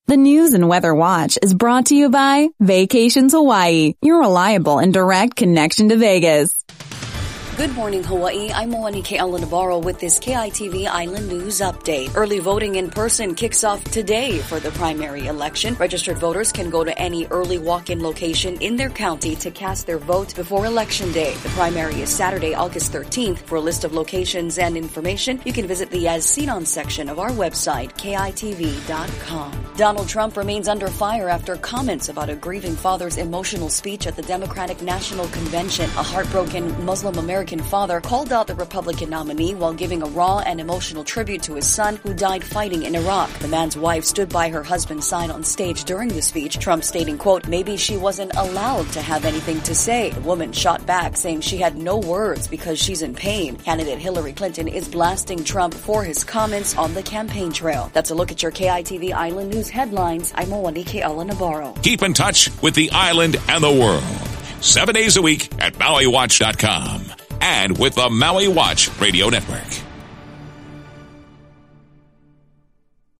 Your daily news brief for August 1, 2016